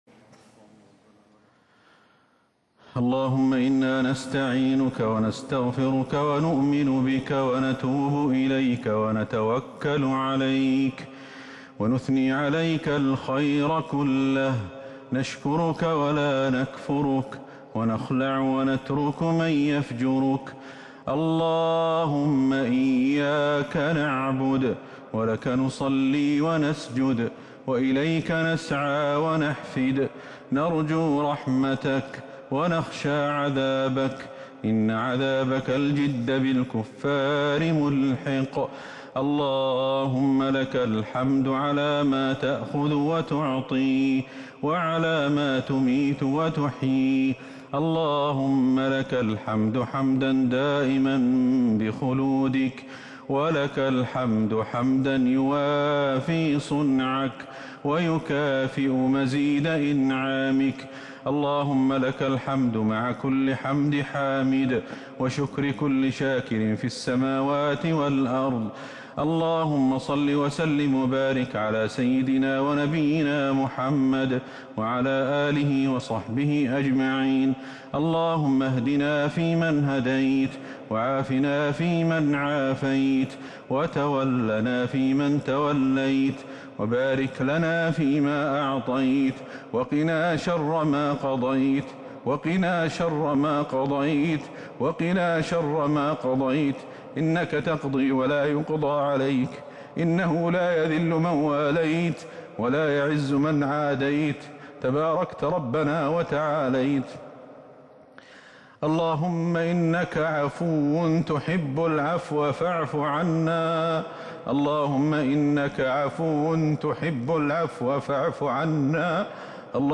دعاء القنوت ليلة 27 رمضان 1441هـ > تراويح الحرم النبوي عام 1441 🕌 > التراويح - تلاوات الحرمين